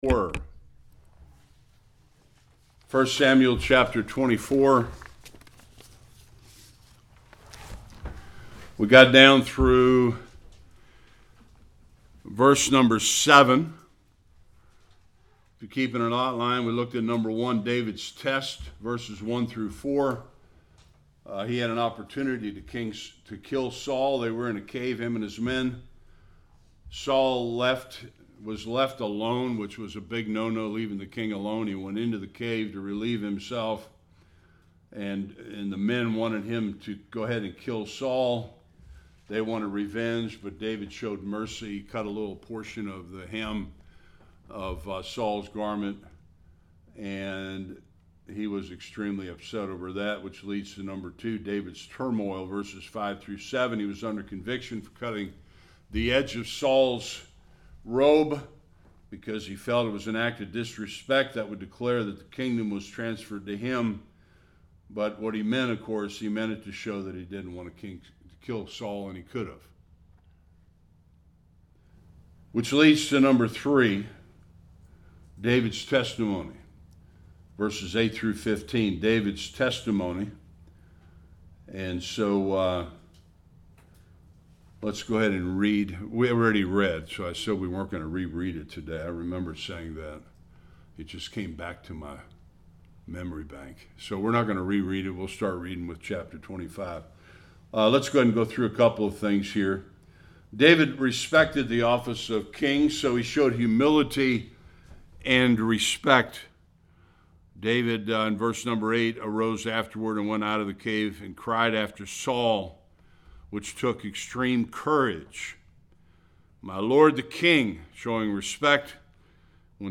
1-13 Service Type: Sunday School Wicked Nabal infuriates David almost leading him to a horrible decision.